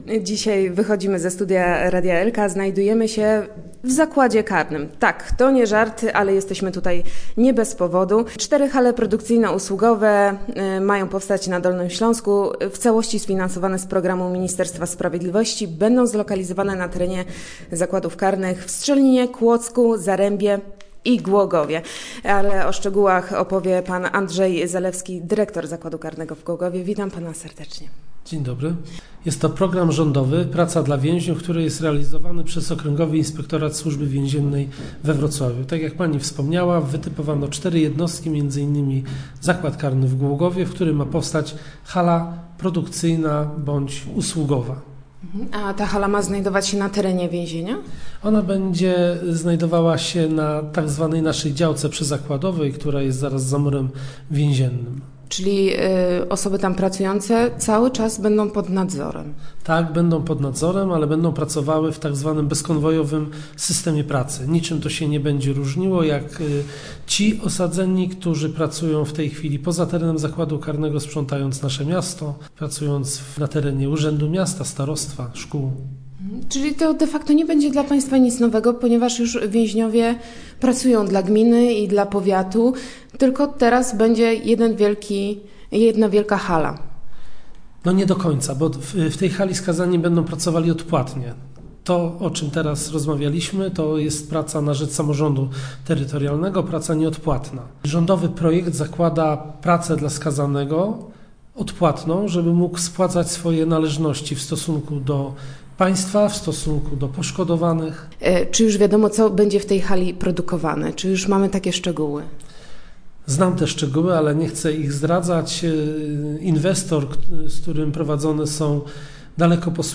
Jak ma wyglądać praca więźniów w tych halach, jak mają one funkcjonować i jakie dać korzyści nie tylko przedsiebiorcy, lecz także osadzonym? Aby dowiedzieć się więcej wyszliśmy ze studia i pojechaliśmy z naszym radiowym mikrofonem do więzienia.